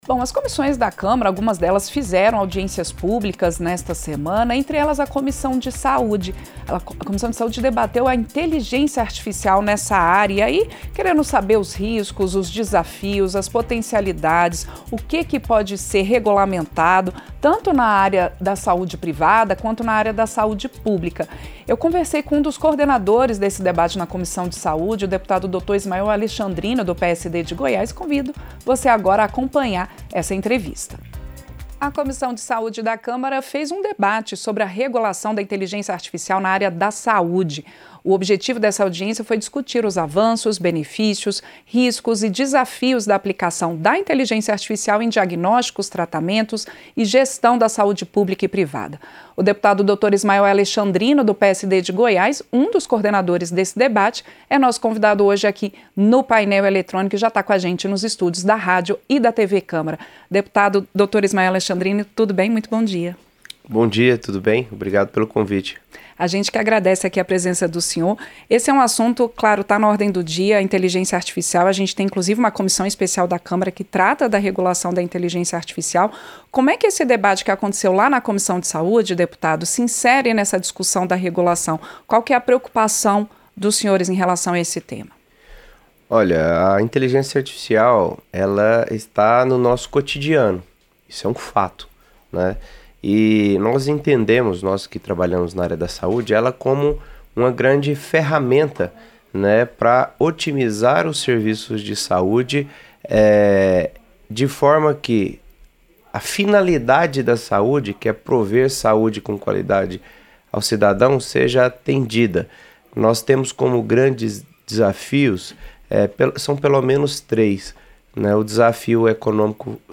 Entrevista - Dep. Dr. Ismael Alexandrino (PSD-GO)